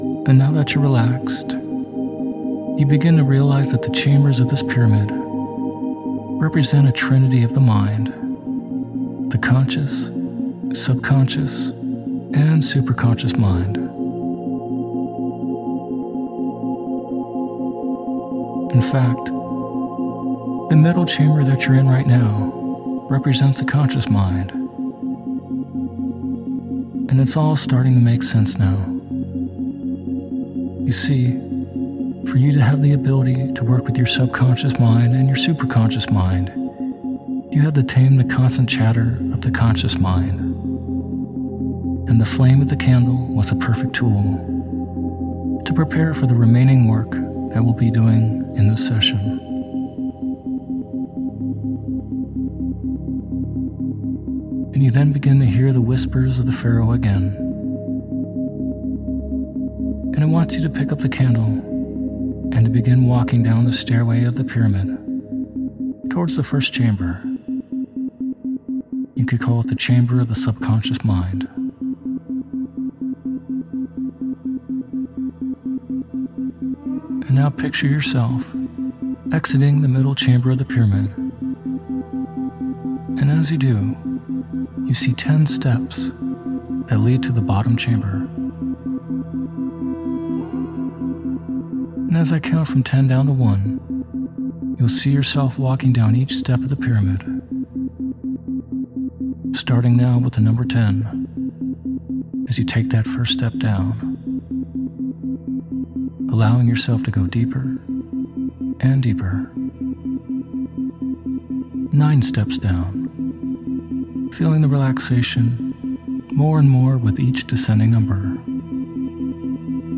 Hypnosis For Shadow Work and Activating Your Higher Mind With Isochronic Tones
In the hypnosis audio (or guided meditation) you’ll be visiting an ancient pyramid where an old Pharaoh will help you to do do shadow work and to activate your higher mind.